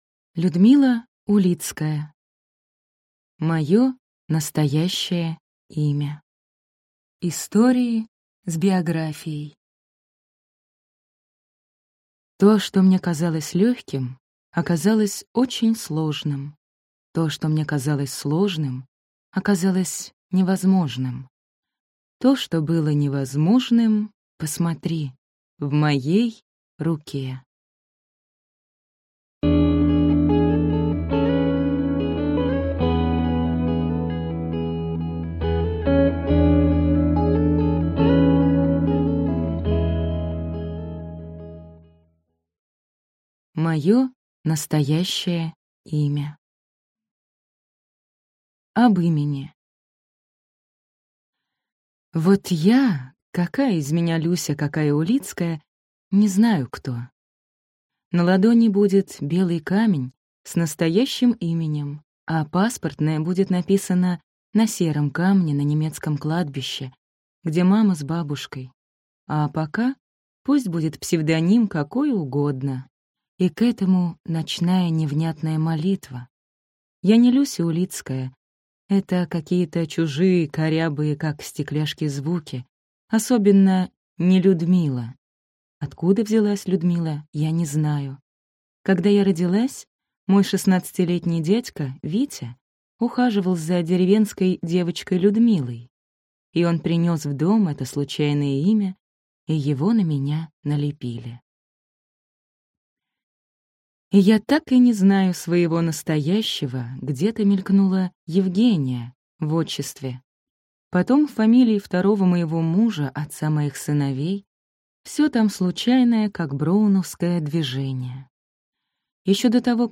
Аудиокнига Моё настоящее имя. Истории с биографией | Библиотека аудиокниг